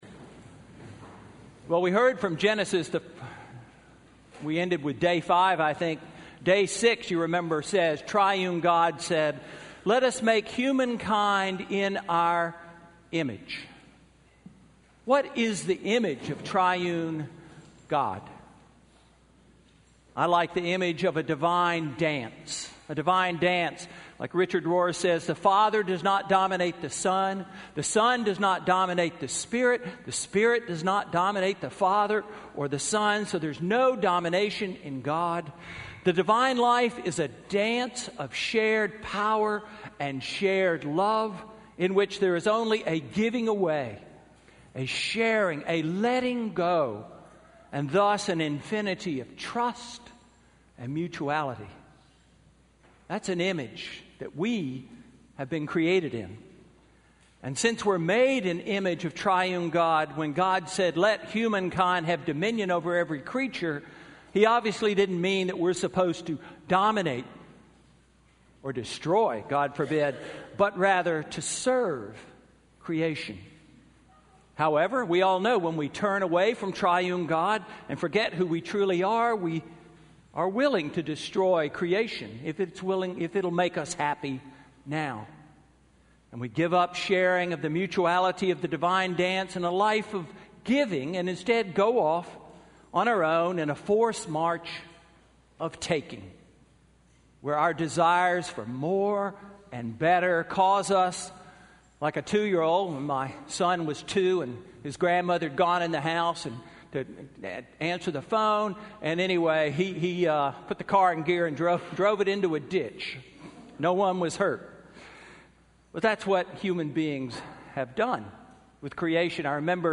Sermon:Creation Care Sunday–October 2, 2016